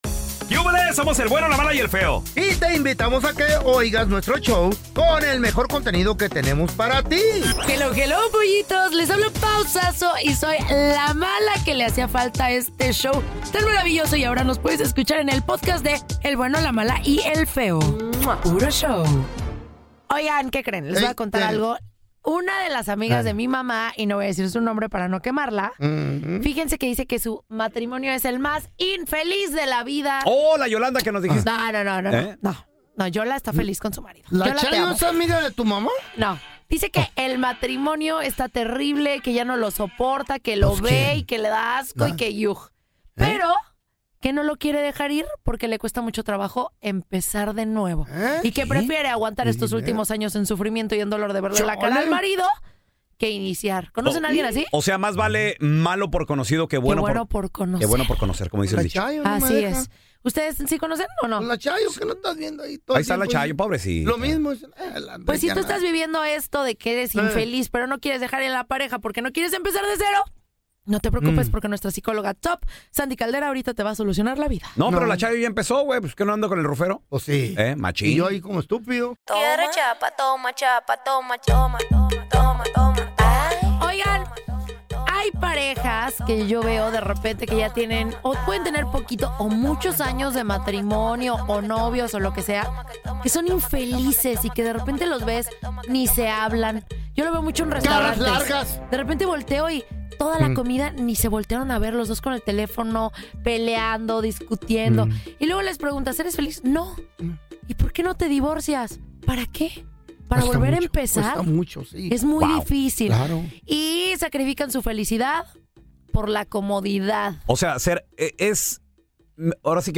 Comedia Improvisada
Charlas Graciosas